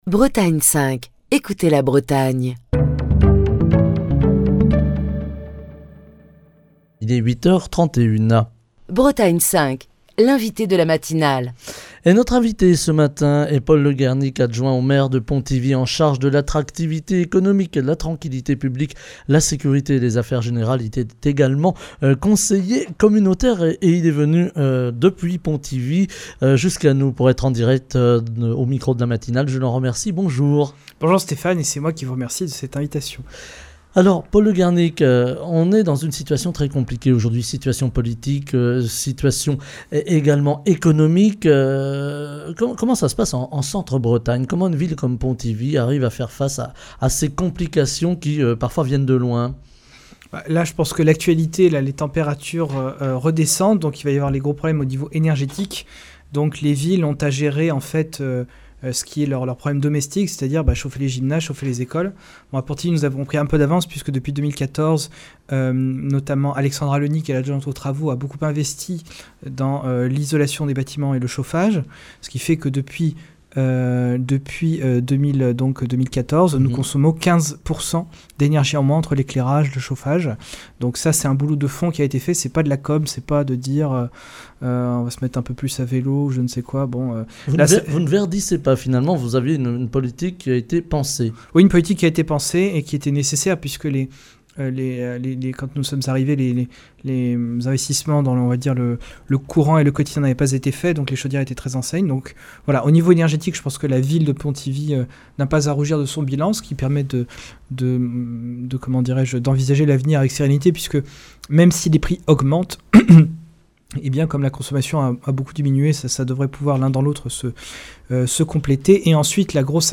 Paul Le Guernic, adjoint au maire de Pontivy, conseiller communautaire | Bretagne5
Écouter Télécharger Partager le podcast Facebook Twitter Linkedin Mail L'invité de Bretagne 5 Matin